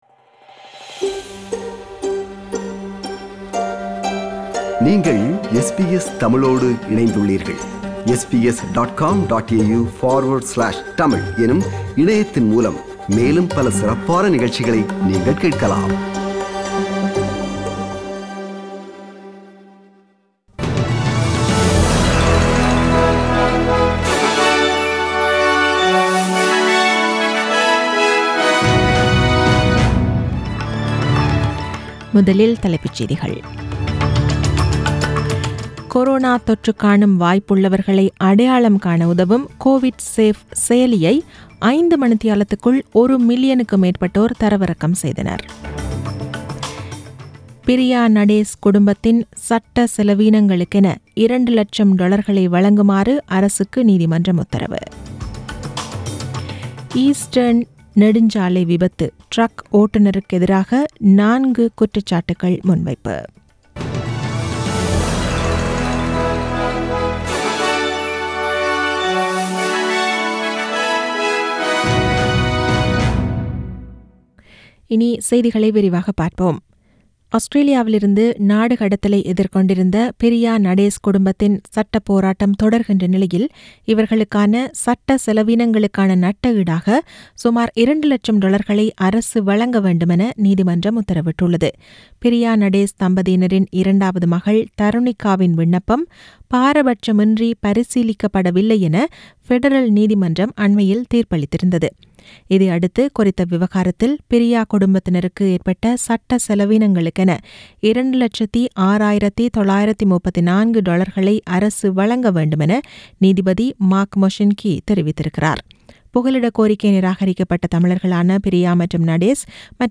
The news bulletin was aired on 27 April 2020 (Monday) at 8pm.